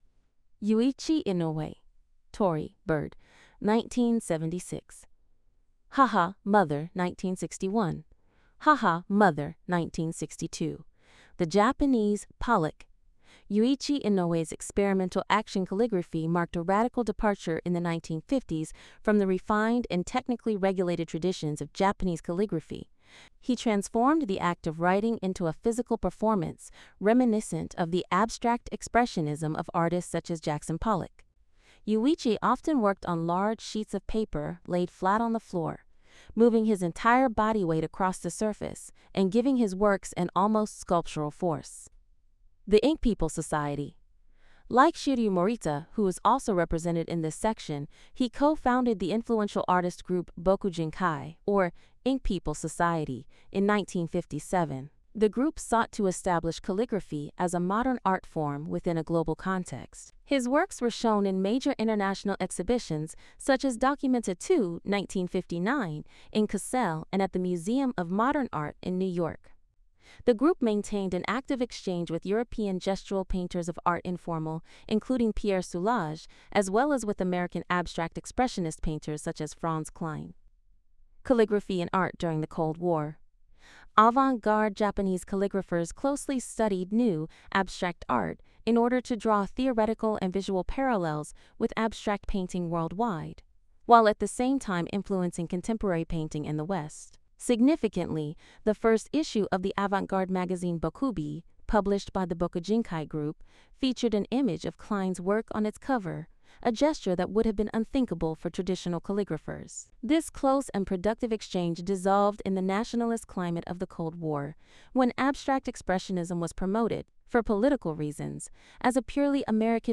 Note: The audio transcription is voiced by an AI.